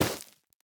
Minecraft Version Minecraft Version snapshot Latest Release | Latest Snapshot snapshot / assets / minecraft / sounds / block / netherwart / break3.ogg Compare With Compare With Latest Release | Latest Snapshot
break3.ogg